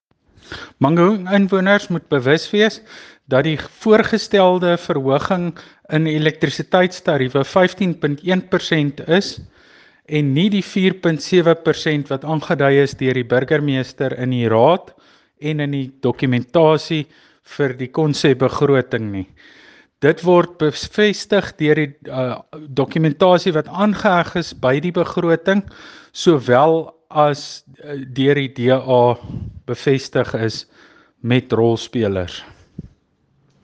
Afrikaans soundbites by Cllr Tjaart van der Walt and